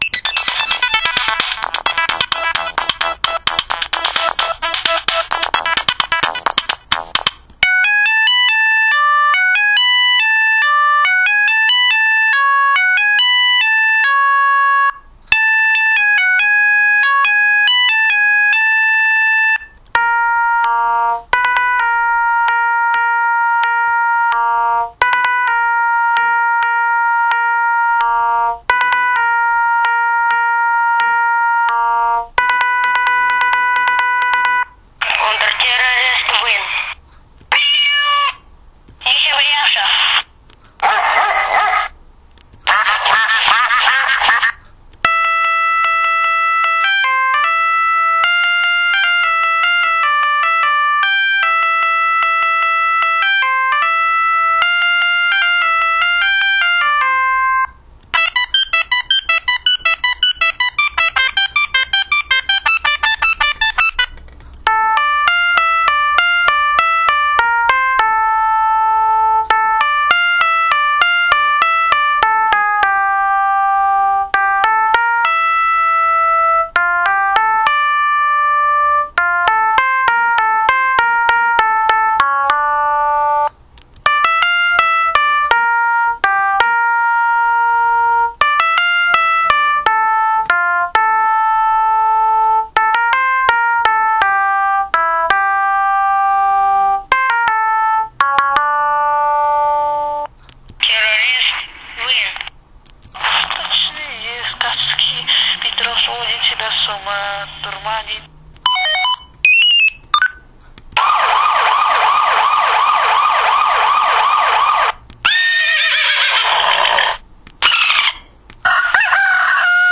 Микрофонная запись